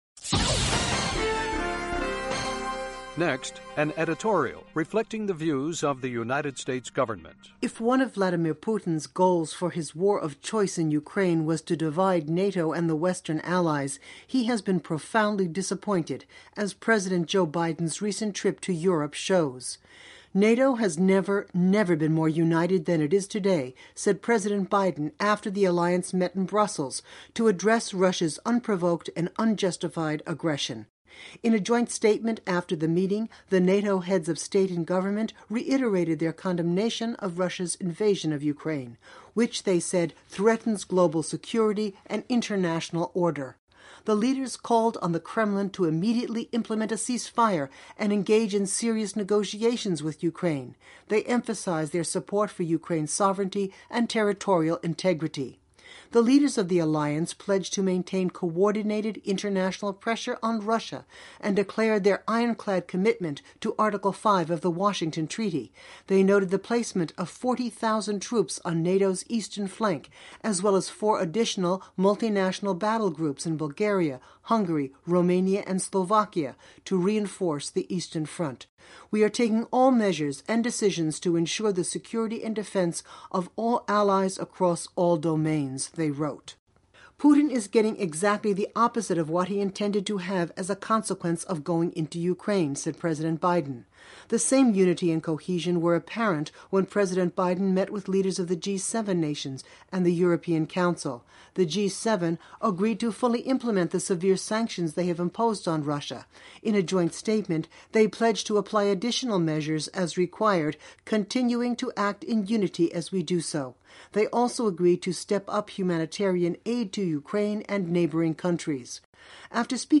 Reflecting the Views of the U.S. Government as Broadcast on The Voice of America